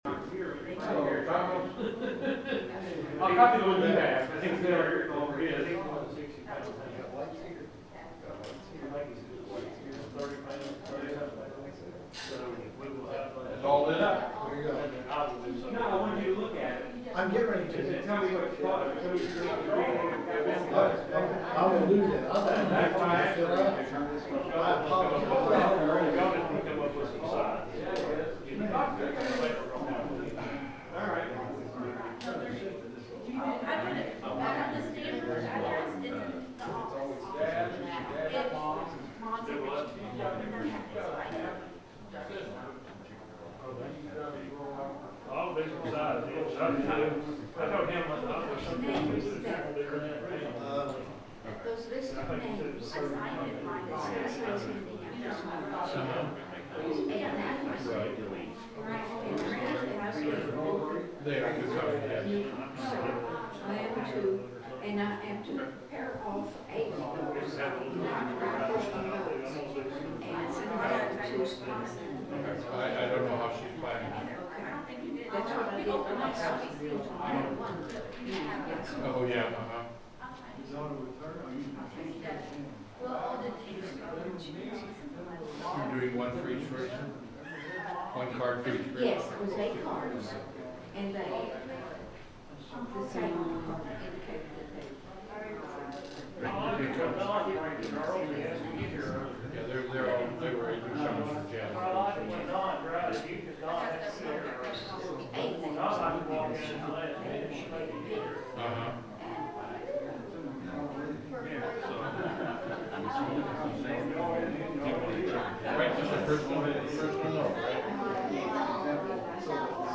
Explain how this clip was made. The sermon is from our live stream on 2/8/2026